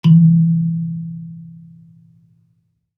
kalimba_bass-E2-mf.wav